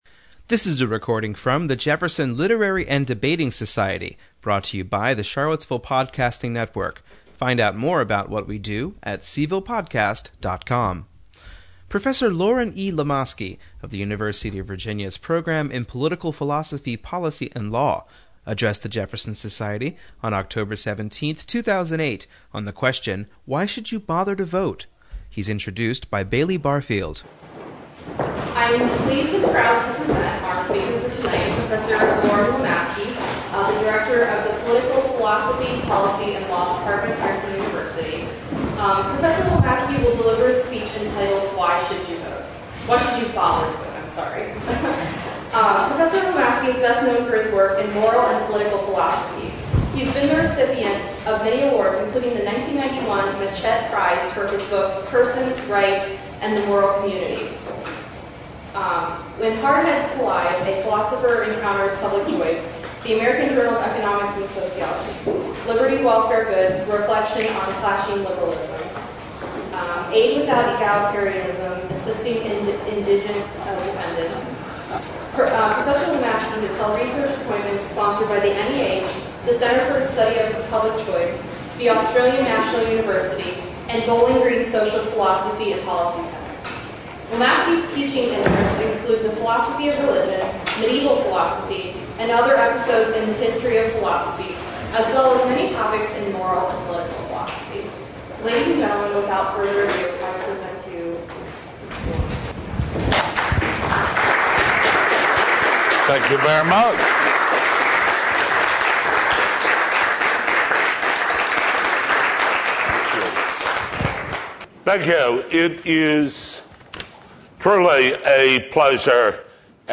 A lively discussion followed.